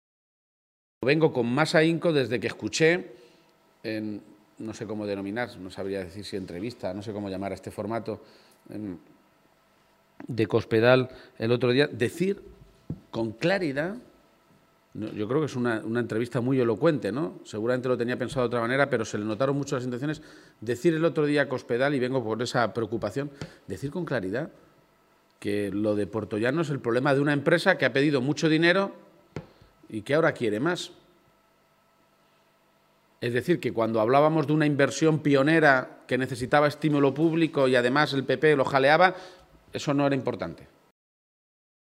El candidato a Presidente de Castilla-La Mancha hacía estas declaraciones en una comparecencia ante los medios de comunicación en la ciudad minera, después de mantener una reunión con el Comité de Empresa de Elcogás, donde ha recibido información de primera mano de cómo está la negociación que están llevando a cabo tanto con el ministerio de industria, como con la propia empresa, que ha anunciado el cierre en diciembre si el Estado no cambia el marco que regula las ayudas públicas a este tipo de industrias energéticas.